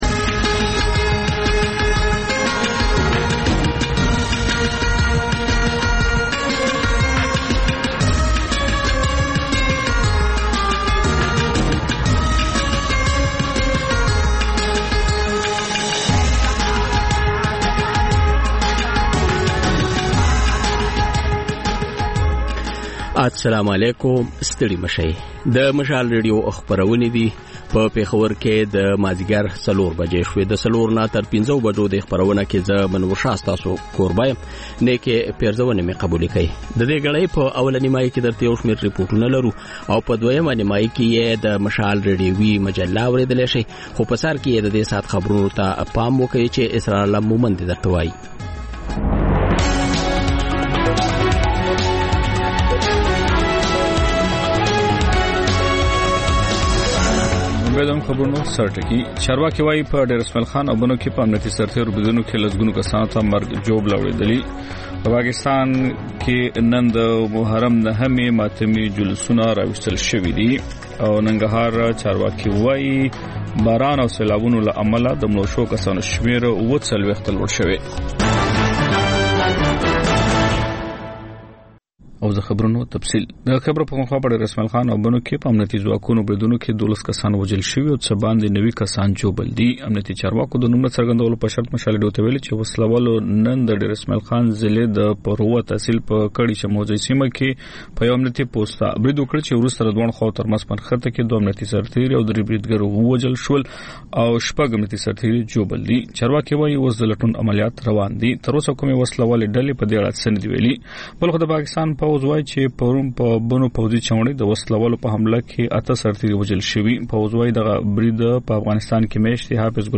د خپرونې پیل له خبرونو کېږي، ورسره اوونیزه خپرونه/خپرونې هم خپرېږي.